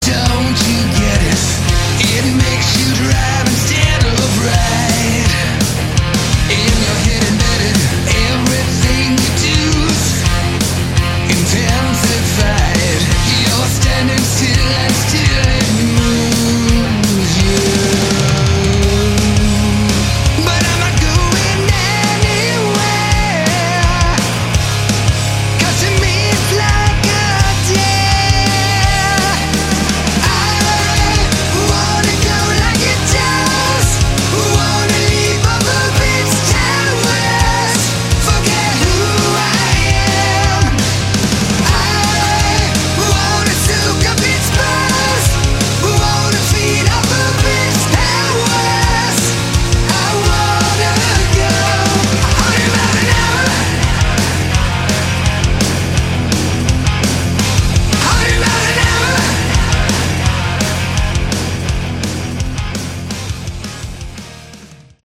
Category: Melodic Rock
vocals
guitars
bass
drums